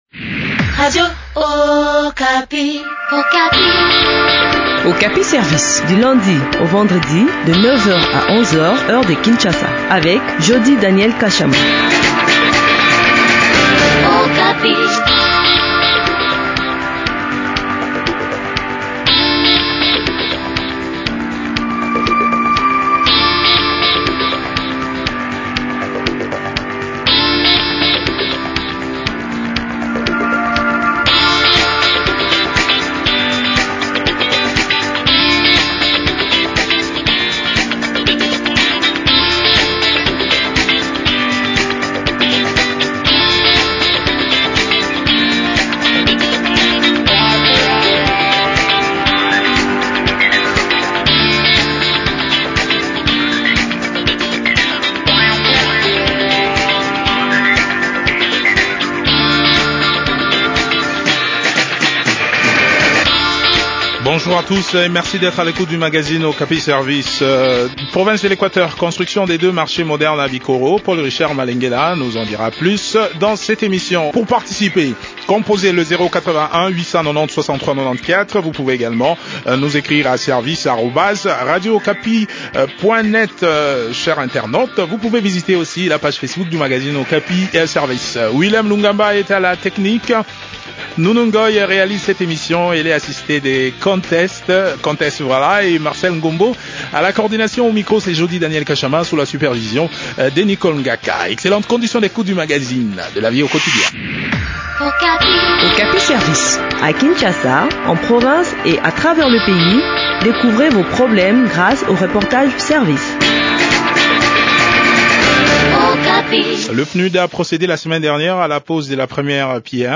Les détails dans cet entretien